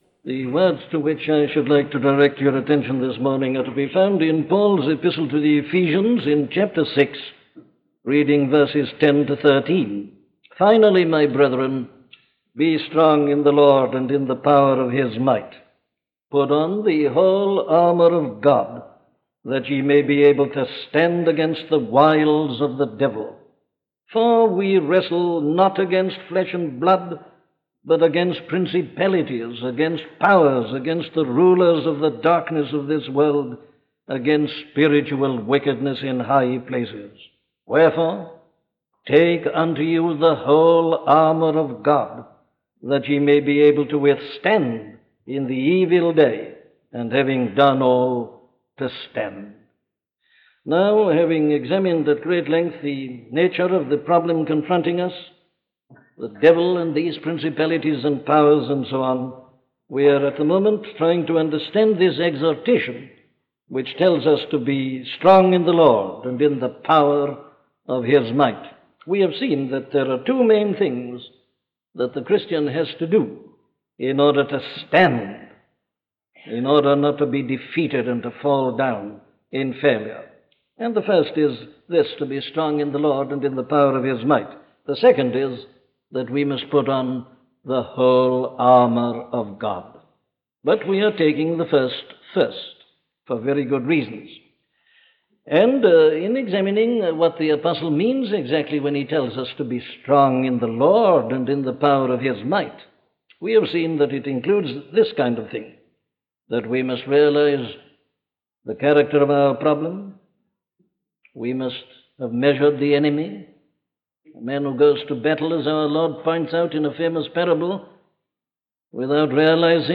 Who Does the Fighting?: A sermon on Ephesians 6:10-13
Listen to the sermon on Ephesians 6:10-13 'Who Does the Fighting?' by Dr. Martyn Lloyd-Jones
In this sermon on fighting in the Christian life from Ephesians 6:10–13 titled “Who Does the Fighting?” Dr. Martyn Lloyd-Jones preaches on spiritual warfare and the responsibility that Christians have to be fighting in their lives.